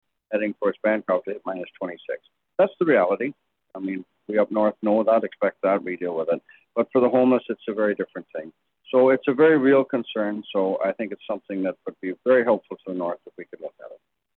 At a county committee meeting Wednesday,Deputy Warden Tony Fitzgerald of Highland Hastings described the temperature early Wednesday morning in the Bancroft area.